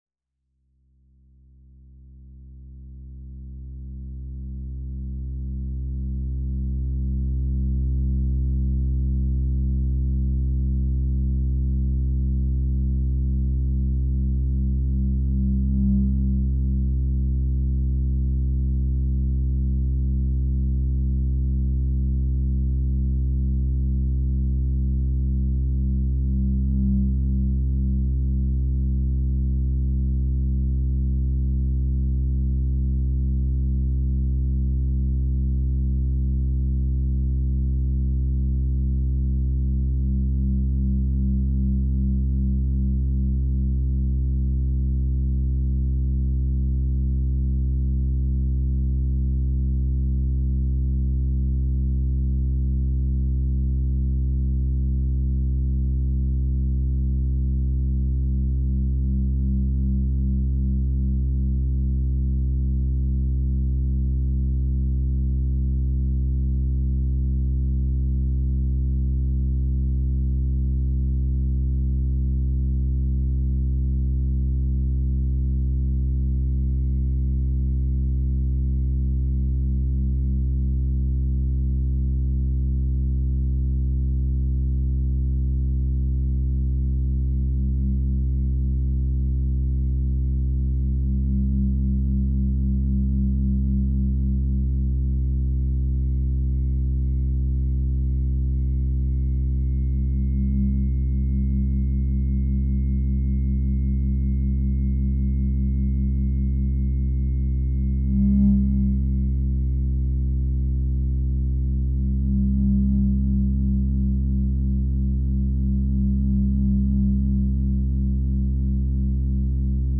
Composition for analogue synth,guitar amp & piano